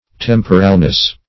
Temporalness \Tem"po*ral*ness\, n.
temporalness.mp3